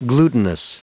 Prononciation du mot glutinous en anglais (fichier audio)
Prononciation du mot : glutinous